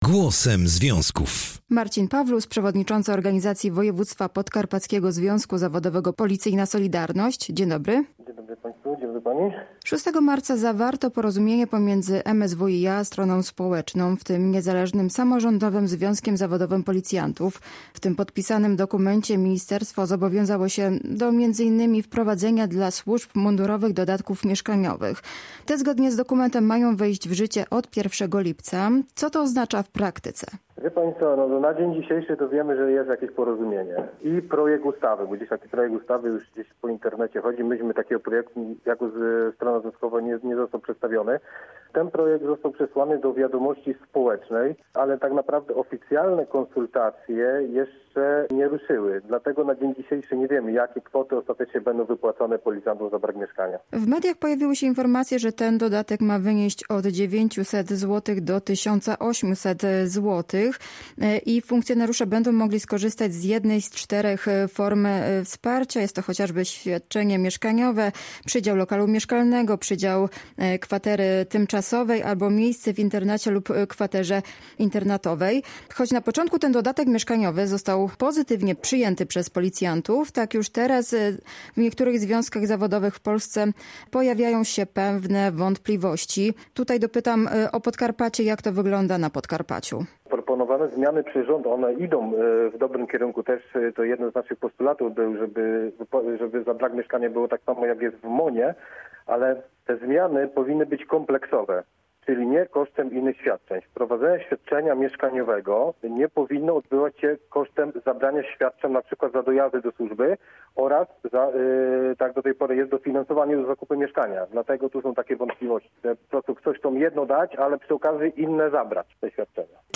Na antenie Polskiego Radia Rzeszów mówił między innymi o tak zwanym dodatku mieszkaniowym i o porozumieniu, jakie strona społeczna zawarła z Ministerstwem Spraw Wewnętrznych i Administracji.